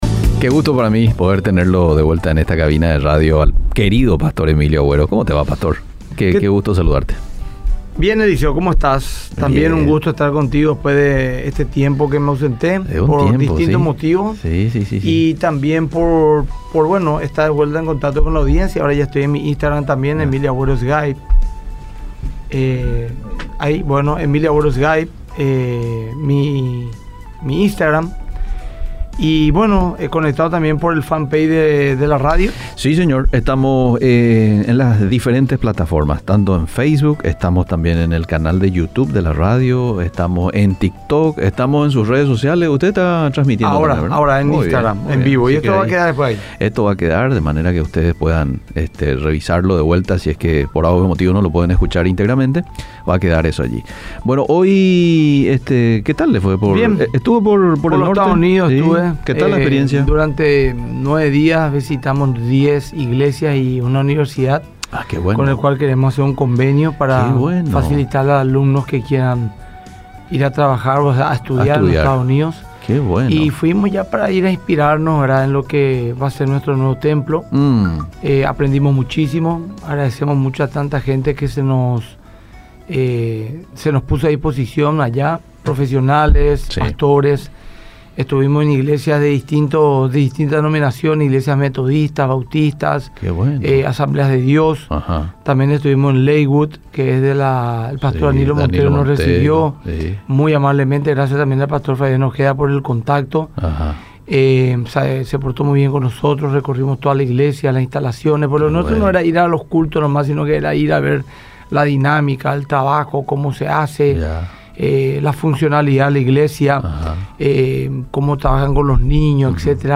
Preguntas y respuestas. ¿Cuál es el propósito de la vida?.
Iglesia Más que Vencedores presenta: Un diálogo sobre aspectos que hacen a nuestro crecimiento, fundamentados sobre la fe.